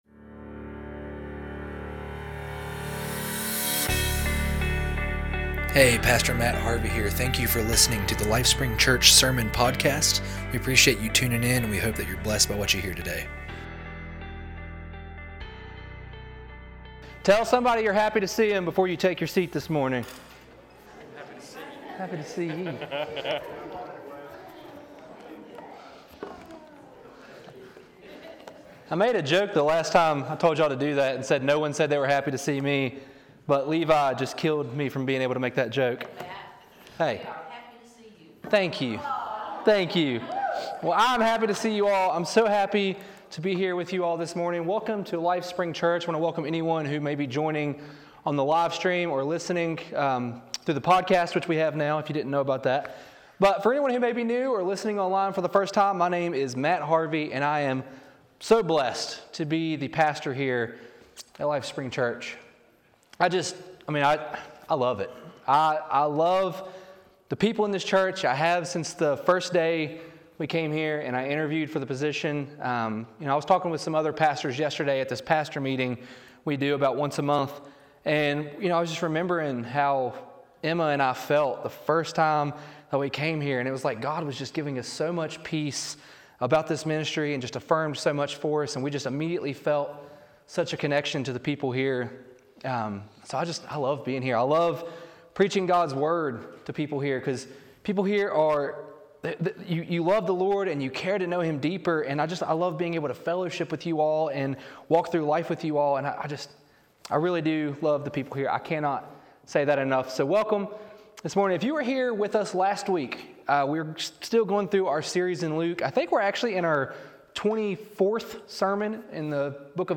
Sermons | LifeSpring Church